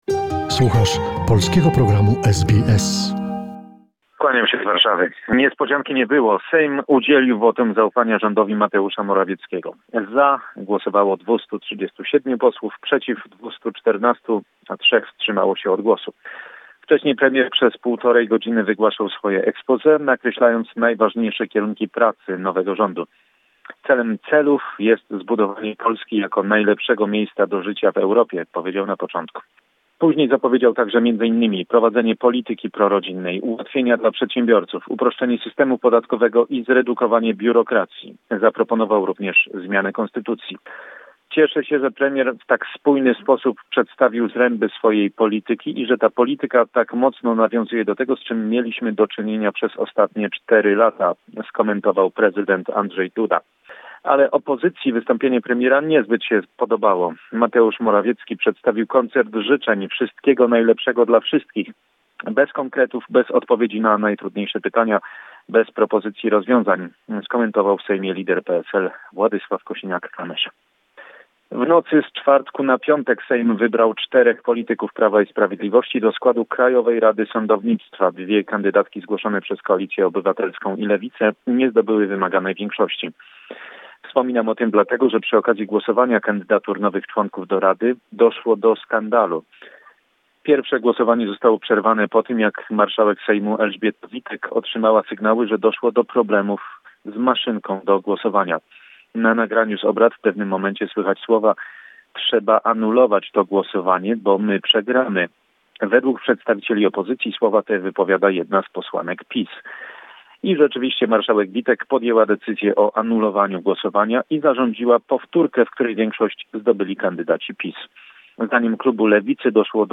weekly report from Poland